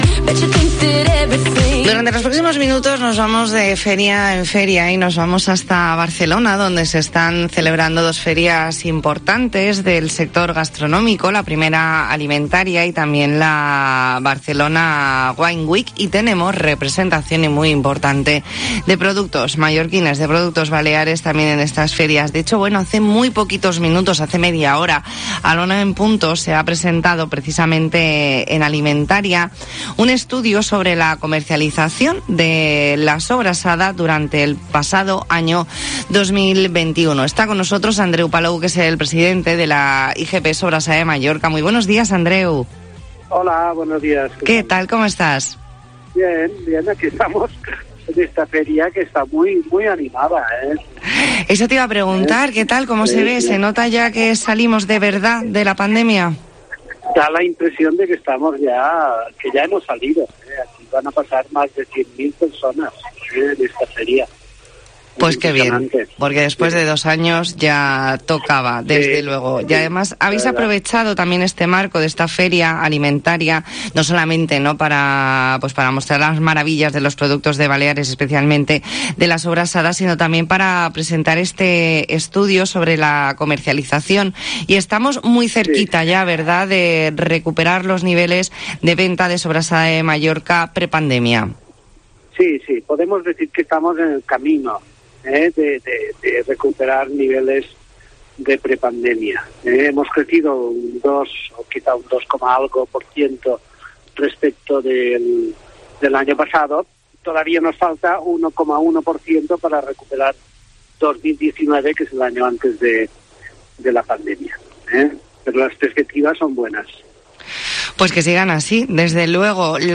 E ntrevista en La Mañana en COPE Más Mallorca, lunes 4 de abril de 2022.